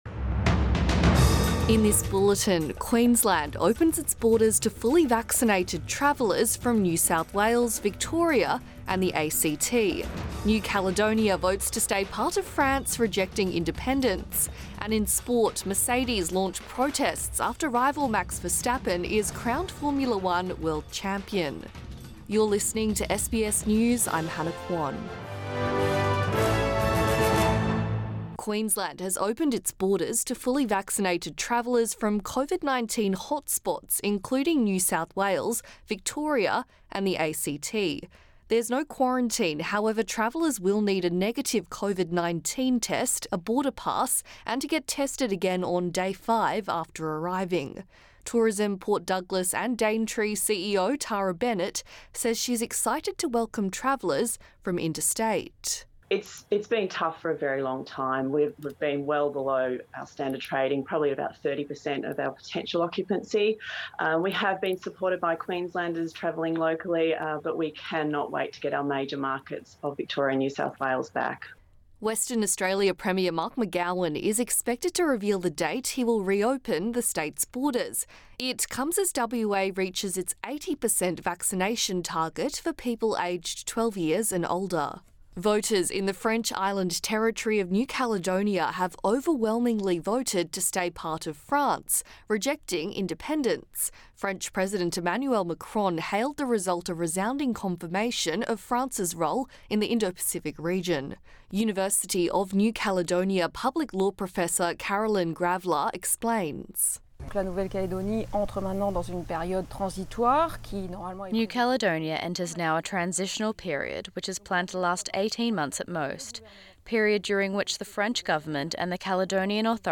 AM bulletin 13 December 2021